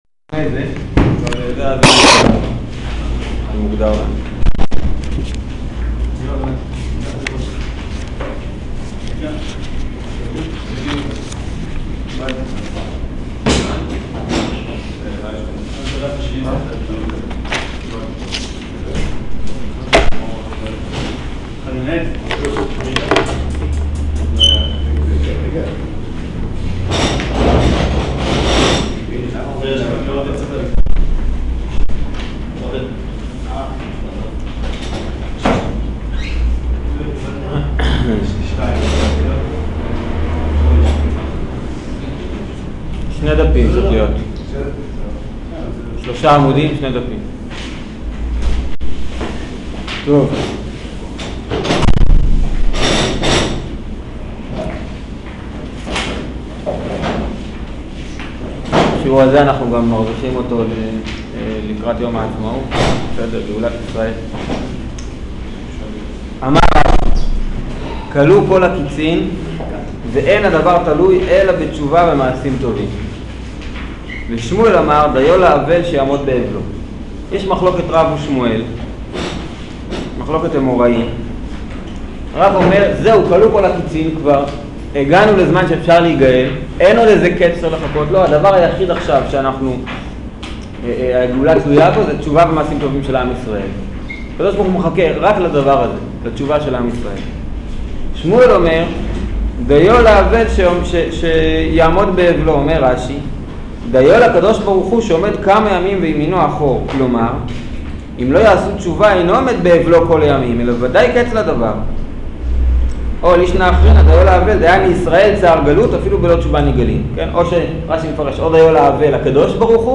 שיעור שאלה שאין עליה תשובה